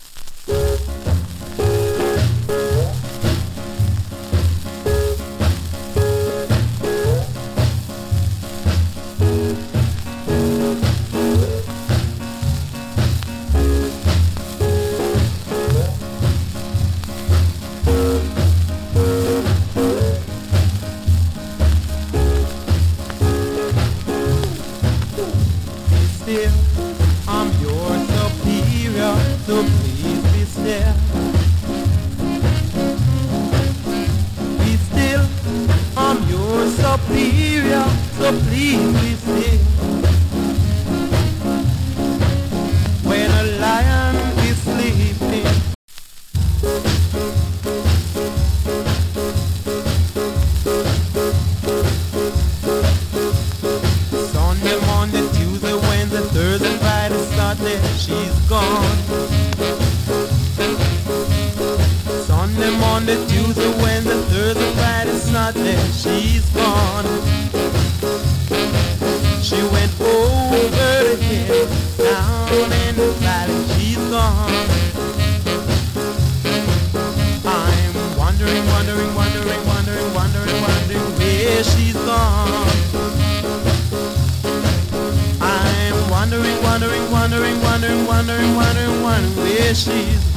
HISS NOISE 有り。
GREAT EARLY SKA !